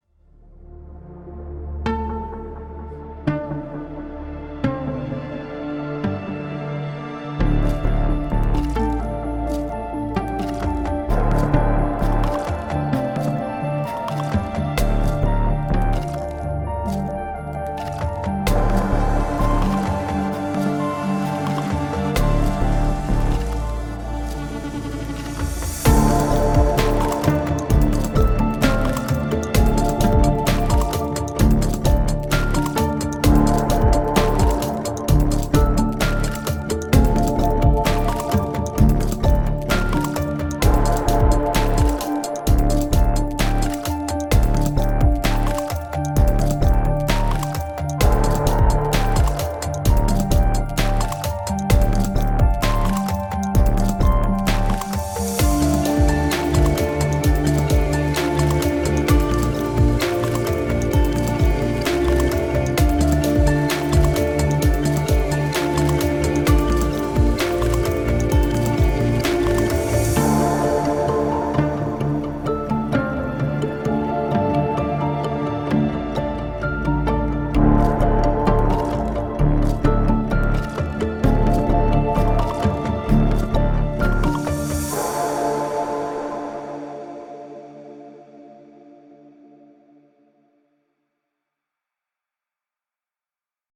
tema dizi müziği, duygusal heyecan gerilim fon müziği.